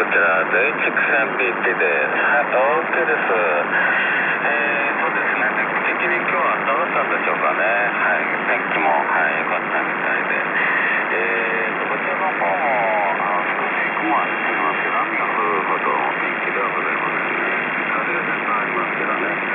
いずれもSSBで復調したWAVファイルです。
ジャーというかビャーというかそういうノイズで、これは周波数が時間とともにゆっくり移動します。